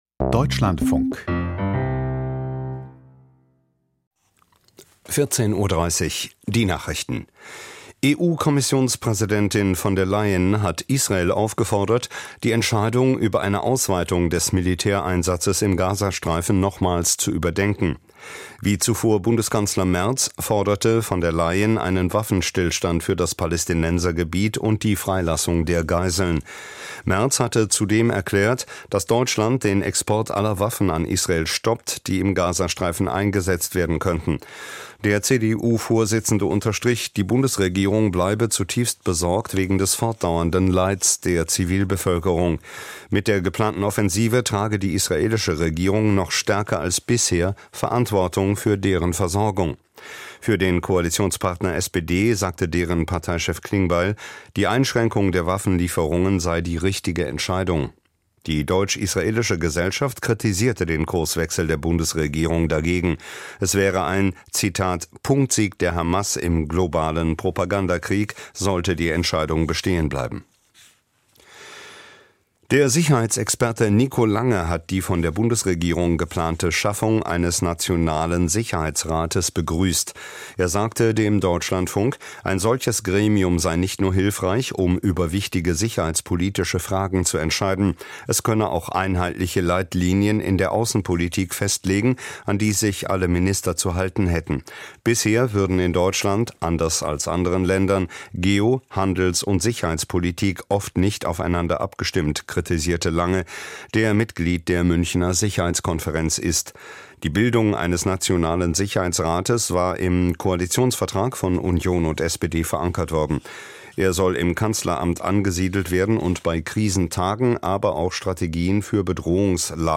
Die Nachrichten vom 08.08.2025, 14:30 Uhr